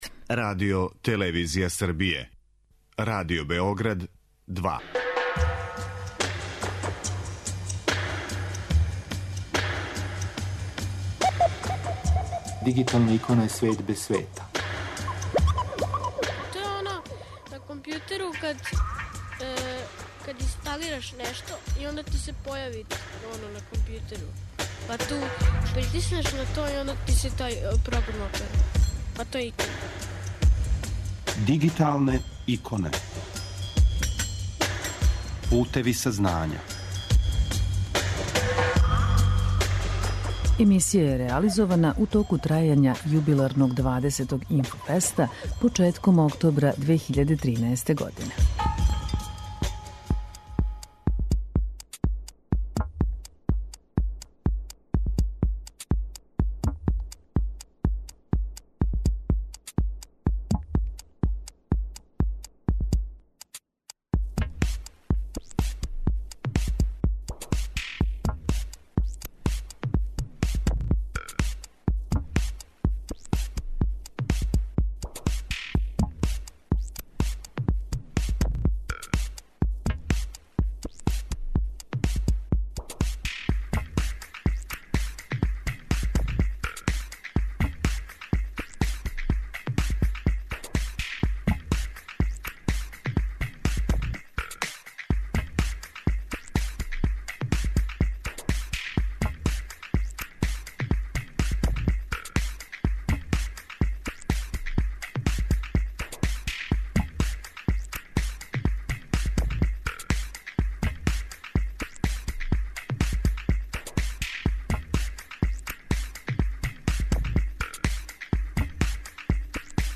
И ова емисија је реализована у току трајања 20. Инфофеста, jедног од највећих информатичких скупова у региону, који је одржан од 29. септембра до 5. октобра прошле године у Будви.